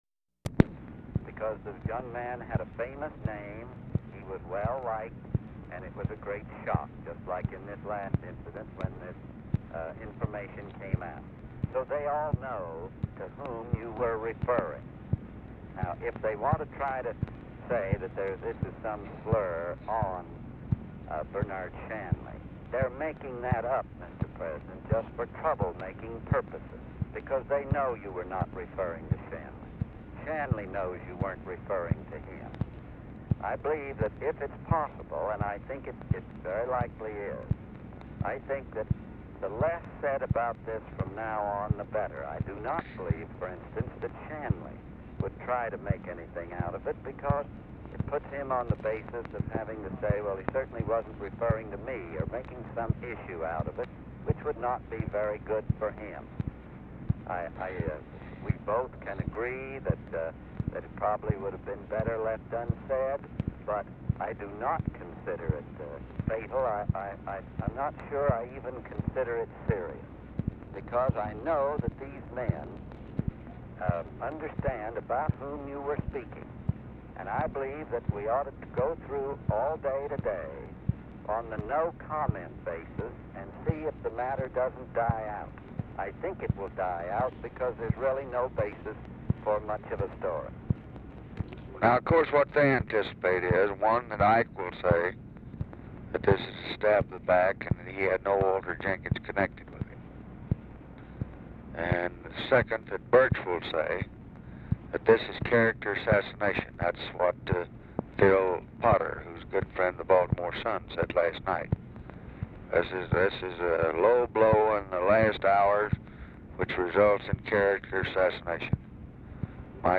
Format Dictation belt
Location Of Speaker 1 Salt Lake City, Utah
Specific Item Type Telephone conversation